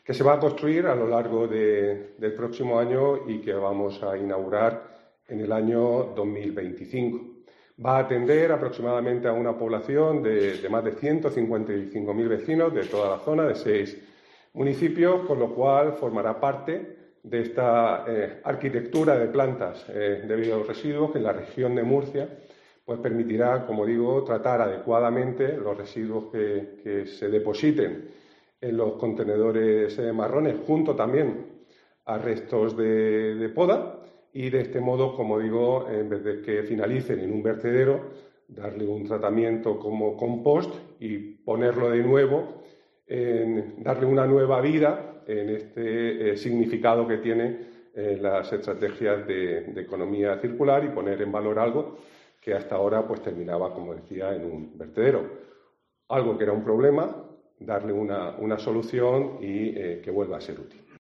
Juan María Vázquez, consejero de Medio Ambiente, Universidades, Investigación y Mar Menor